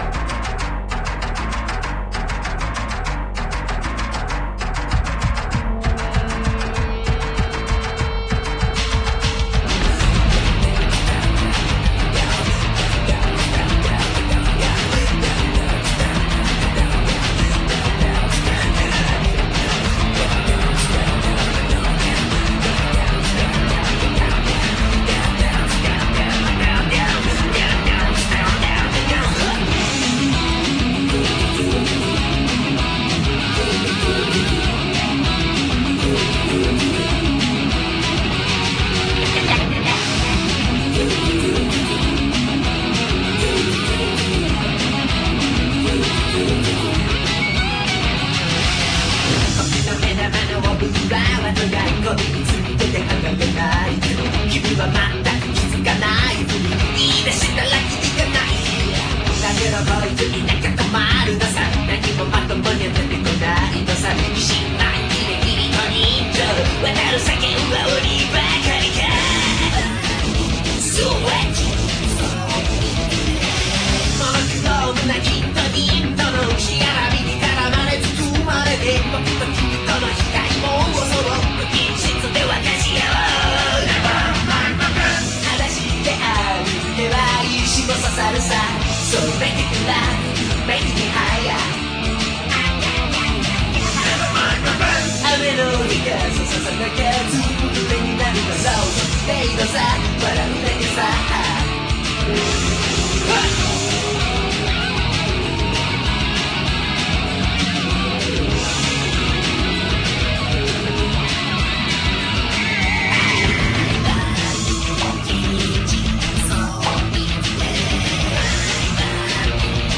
- nice beat, cute guitars and backing vocals. i like it =P